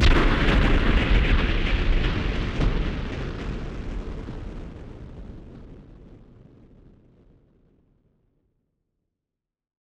BF_SynthBomb_C-06.wav